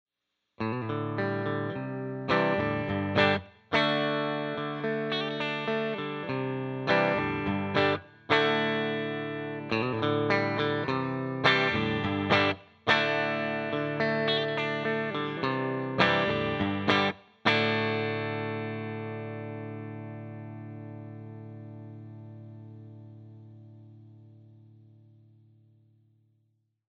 65R/69T used together (middle position)
65R-69T neck and bridge.mp3